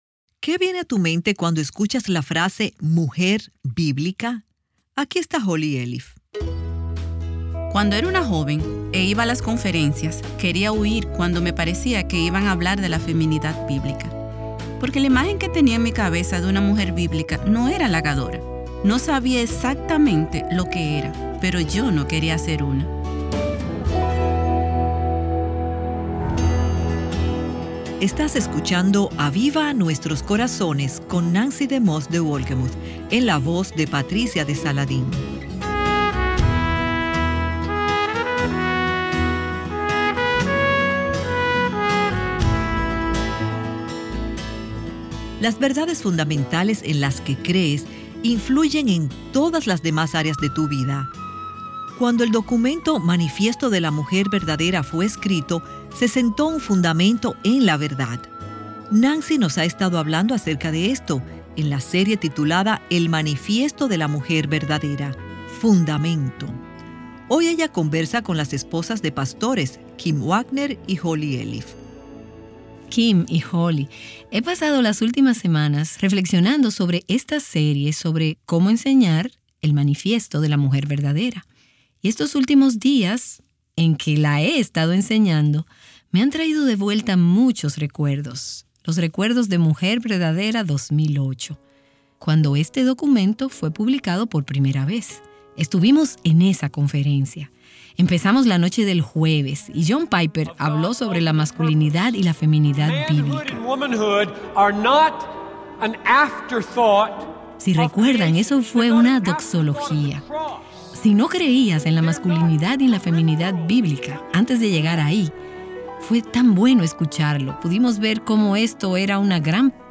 Hoy en nuestro programa tendremos una conversación acerca de algunas experiencias del día en que se lanzó el Manifiesto de la Mujer Verdadera.